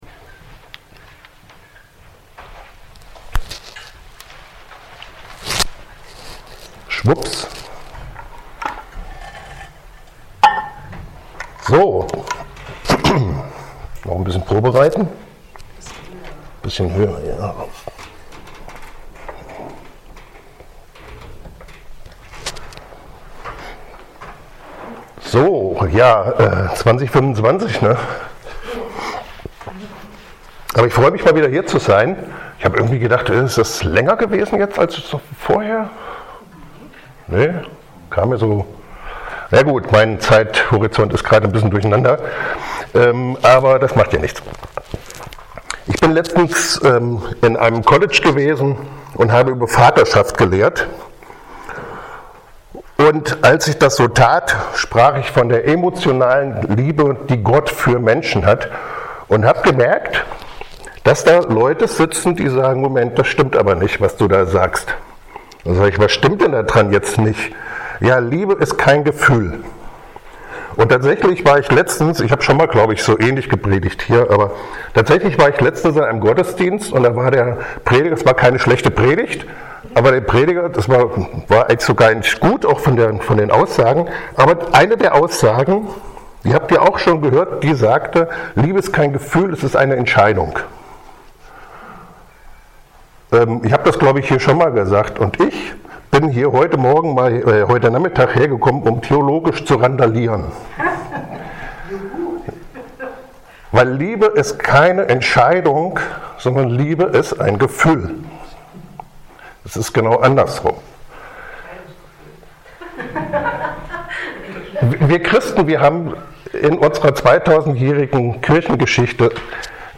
Dienstart: Externe Prediger